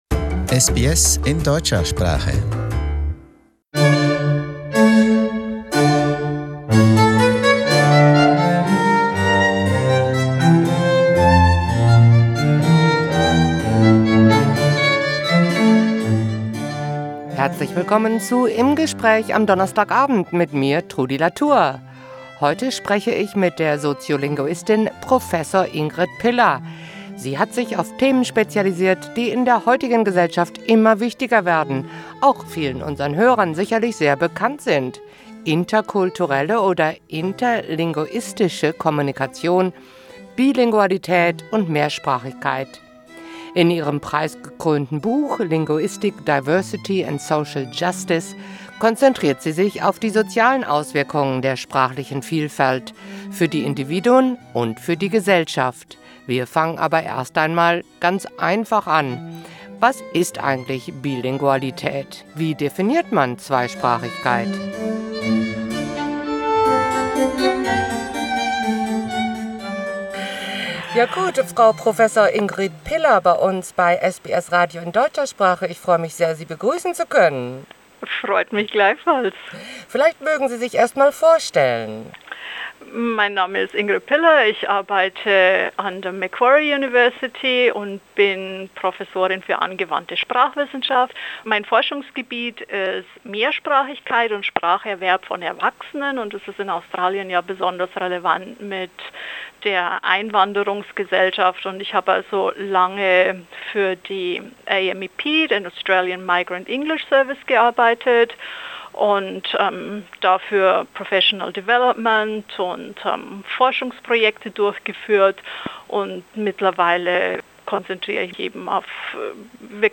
Im Gespräch: Zweisprachigkeit